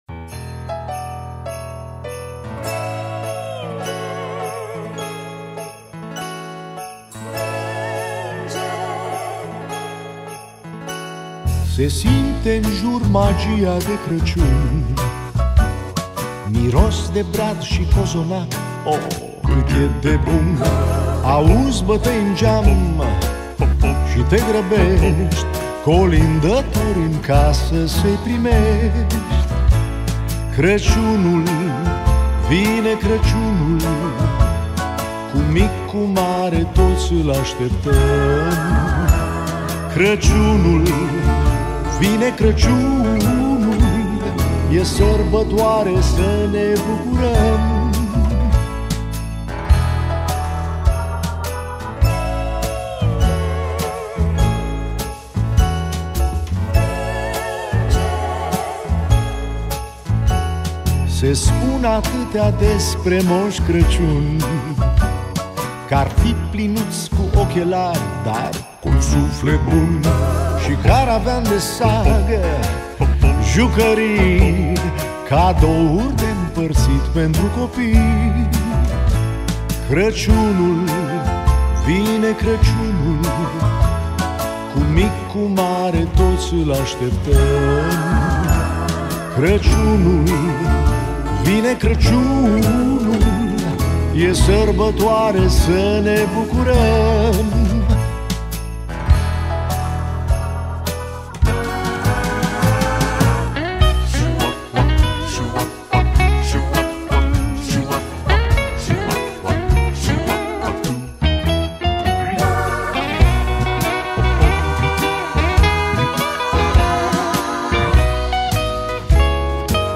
Data: 02.10.2024  Colinde Craciun Hits: 0